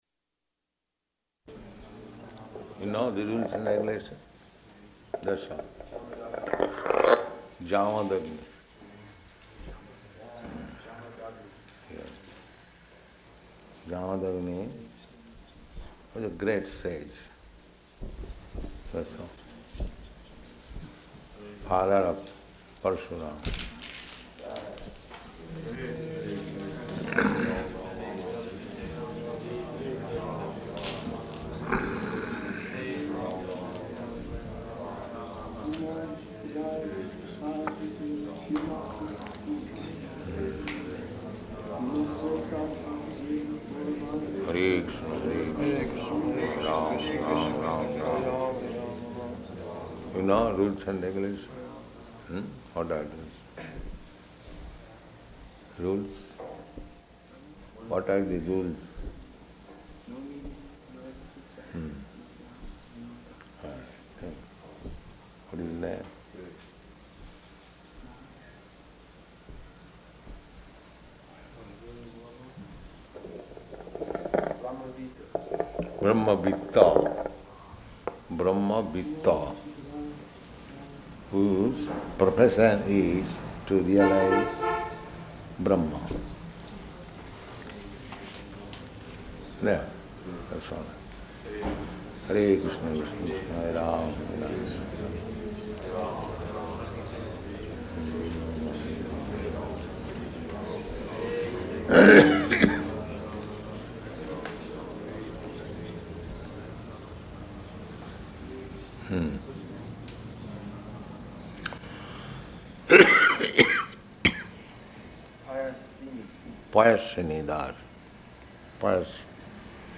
Location: New York